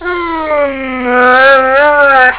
One of the most endearing (and sometimes exasperating) characteristics of the Alaskan Malamute is the fact that they talk.
Mala-talk" is usually sounds such as "oowoo", "roowuf", etc. Be warned, if they talk... they will also "talk back" to you just as a arguing child would.